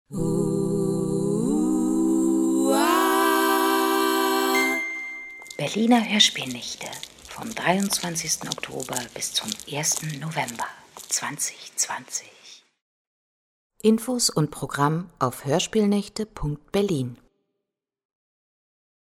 Sprachproben